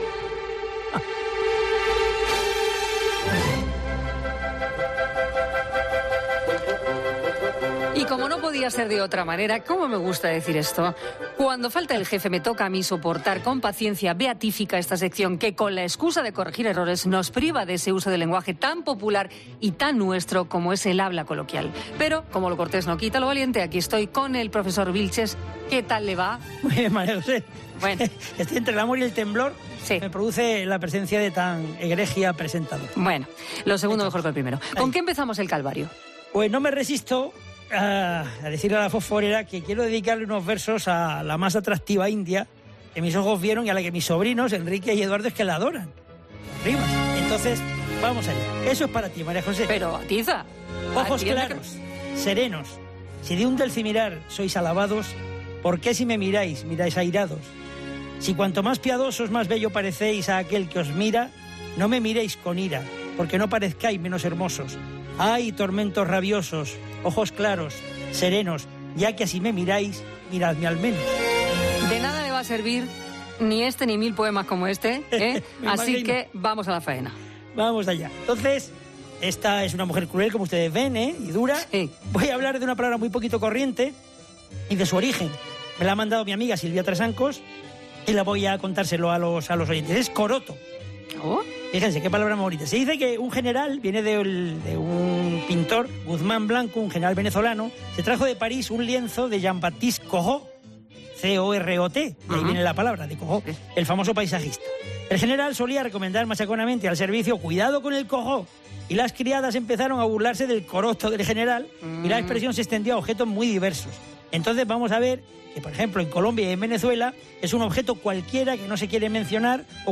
Otro sonido: Albert Rivera hablando de “discrepar con” cuando lo que tiene sentido es discrepar de algo o de alguien.